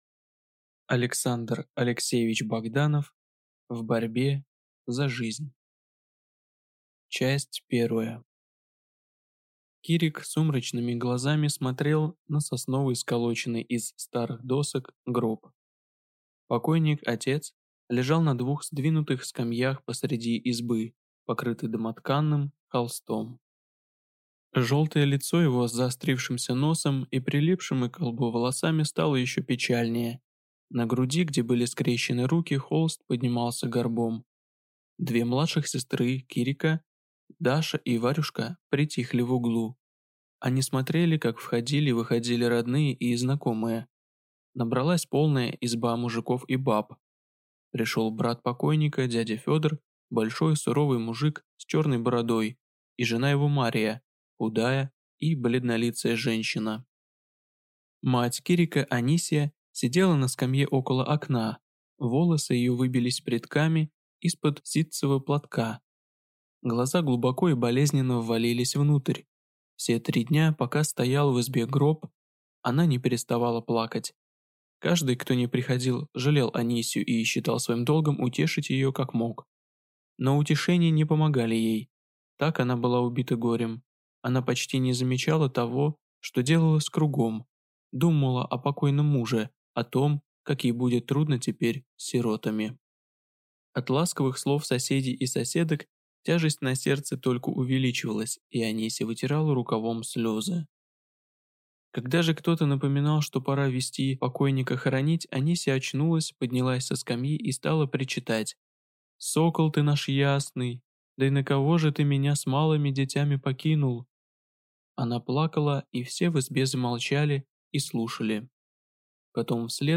Аудиокнига В борьбе за жизнь | Библиотека аудиокниг